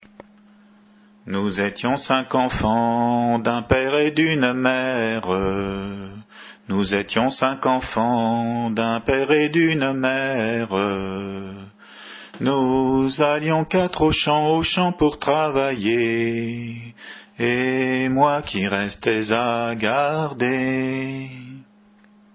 Chants à répondre
Entendu au concours de la Bogue d'Or en octobre 92